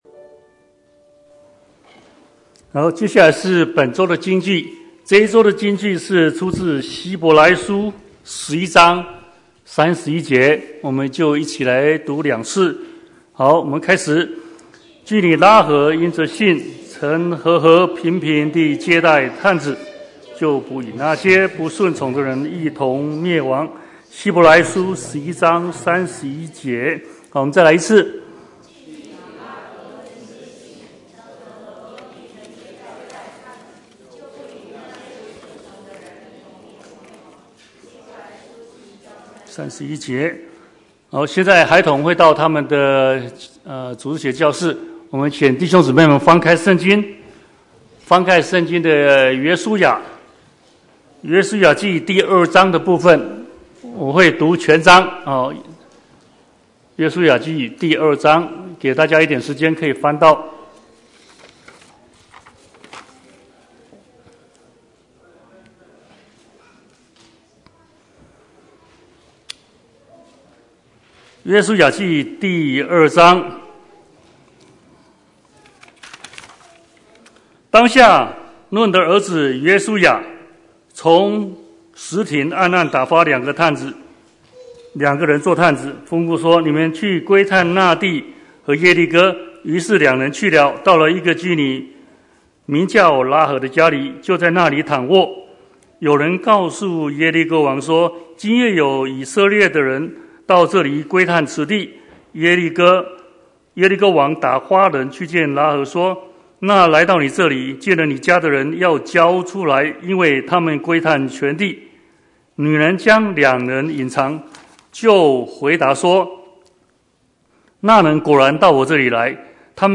Bible Text: 約書亞記 2:1-24 | Preacher: